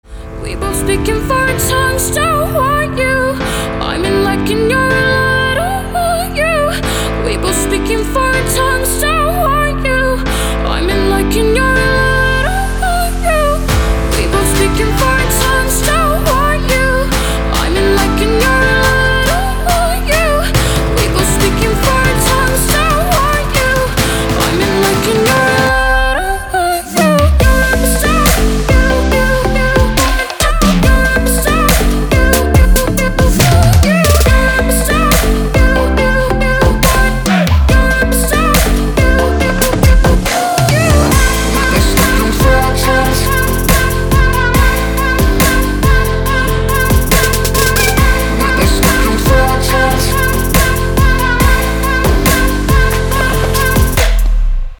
• Качество: 256, Stereo
красивые
женский вокал
Electronic
future bass